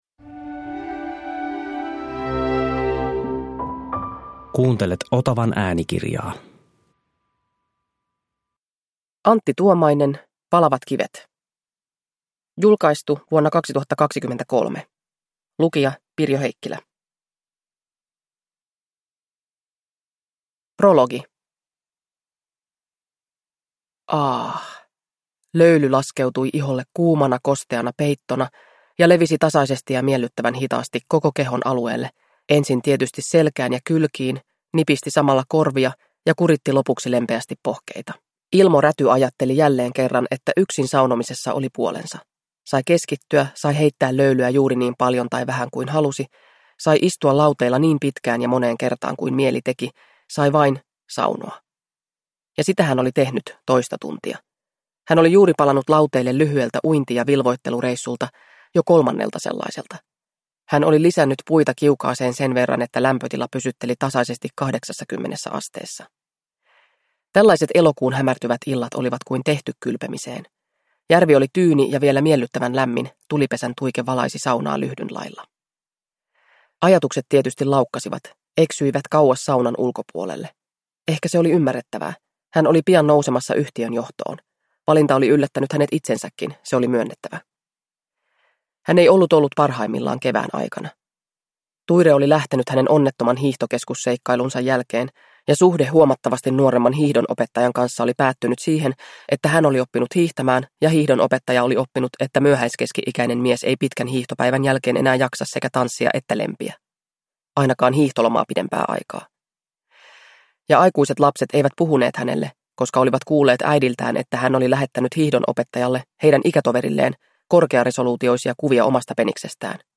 Palavat kivet – Ljudbok